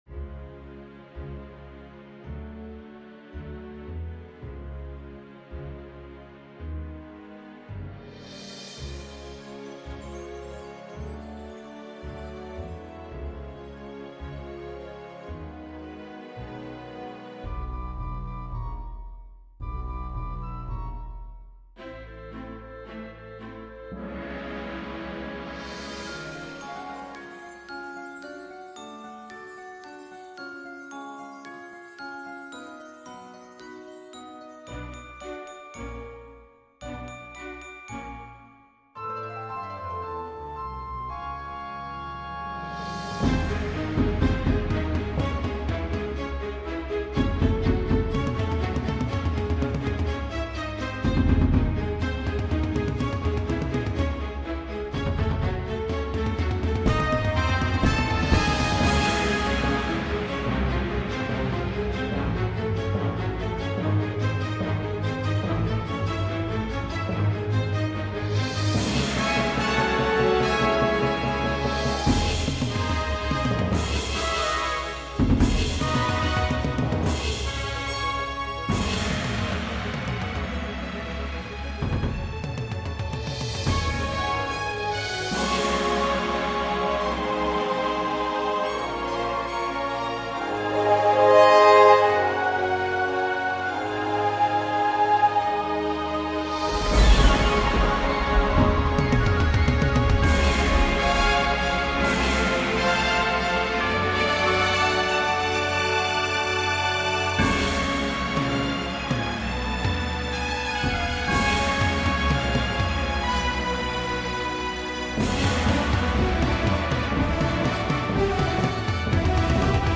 THIS IS JUST THE INSTRUMENTAL.